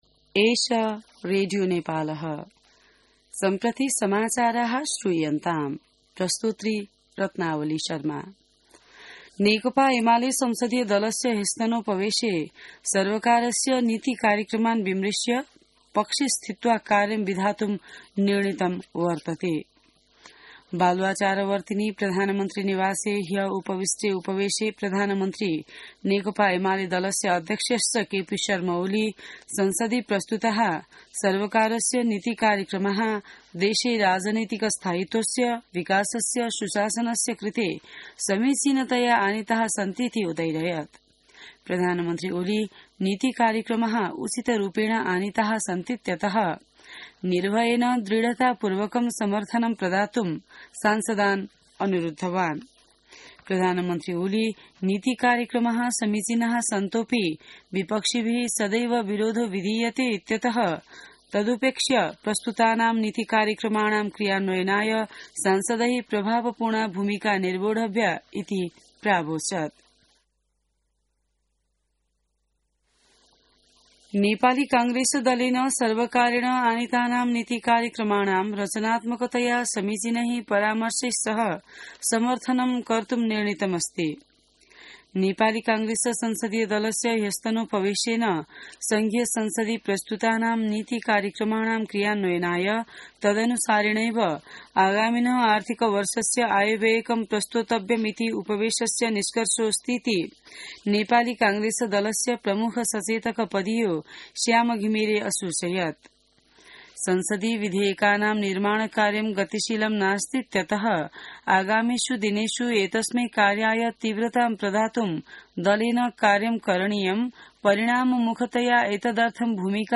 संस्कृत समाचार : २१ वैशाख , २०८२